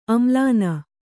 ♪ amlāna